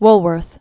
(wlwûrth), Frank Winfield 1852-1919.